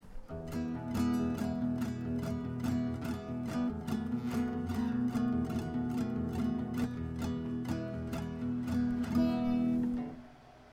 It includes a 7th chord in a bridge to resolve to the major chord of the next verse.
Typical Mexican Resolution